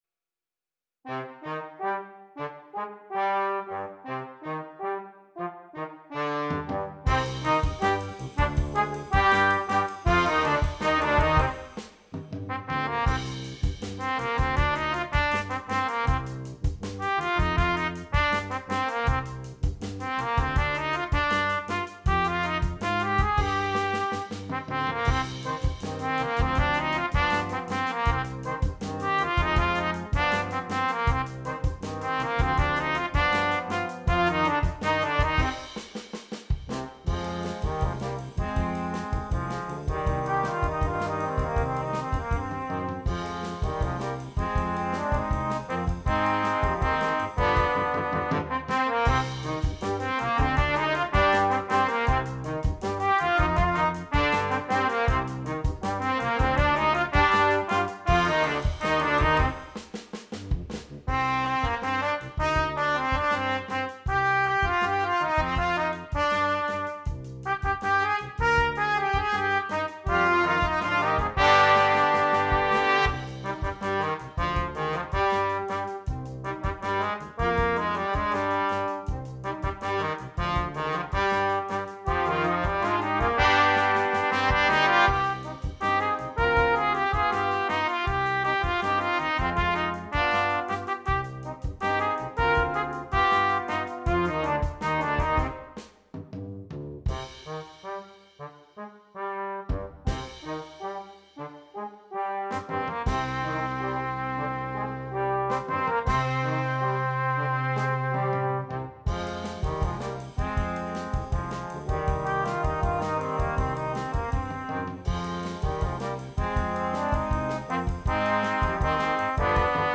Gattung: Für Blechbläserquintett
Besetzung: Ensemblemusik für 5 Blechbläser